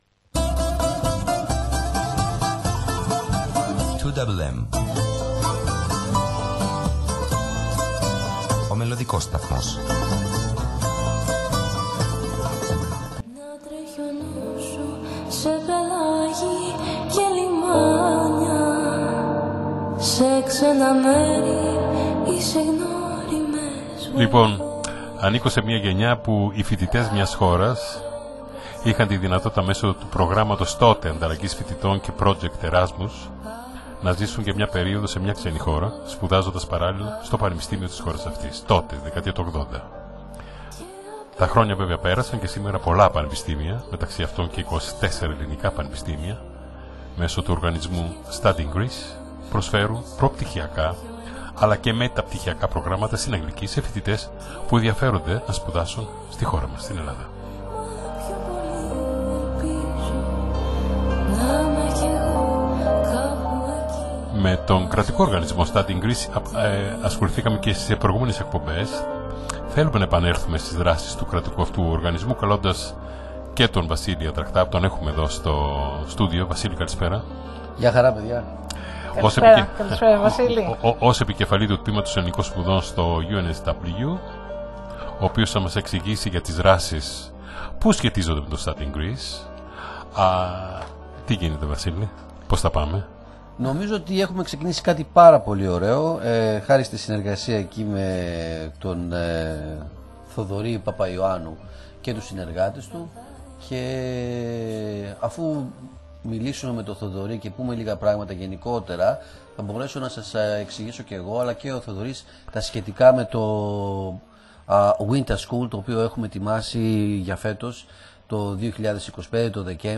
συμμετέχουν ζωντανά στην ραδιοφωνική εκπομπή ” Νυκτερινοί Περίπατοι Ραδιοφώνου” της Πέμπτης 24/07/25, στον ελληνόφωνο ραδιοσταθμό του Σίδνει